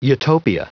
Prononciation du mot utopia en anglais (fichier audio)
Prononciation du mot : utopia